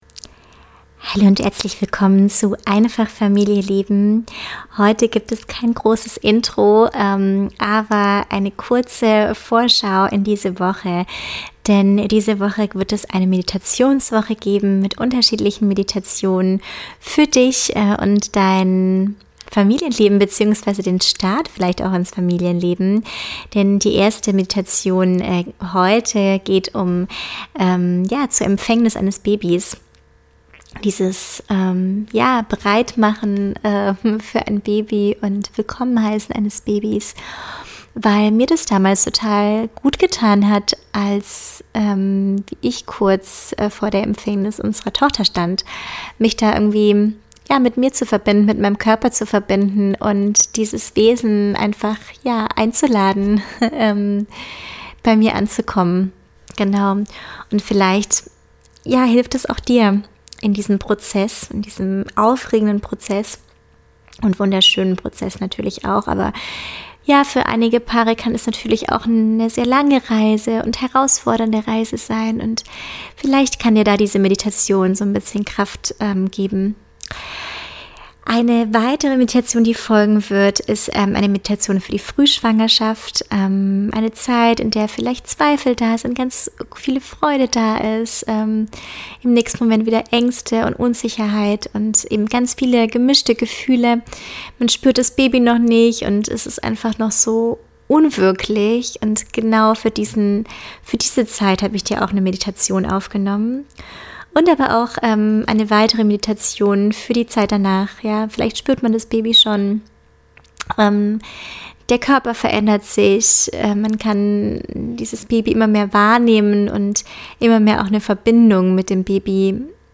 In dieser besonderen Folge lade ich dich zu einer sanften Meditation ein, die dich mit deinem Körper, deinem Herzen und deinem Wunsch nach einem Baby verbindet.